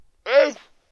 animalworld_seal.ogg